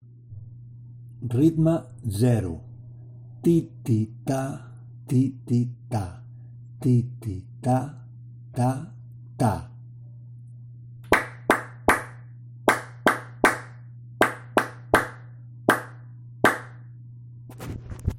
Música
Escolta l’exemple que has rebut en aquest correu i grava’t, llegint primer les síl·labes rítmiques (TA-TI TI, etc.) i després picant.
ritme-0-exemple.mp3